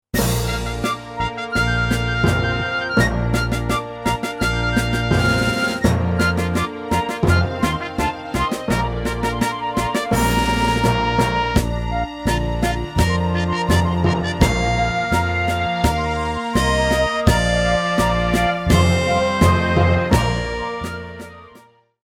For Brass Band or Wind Band